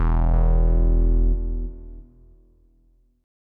bass note02.wav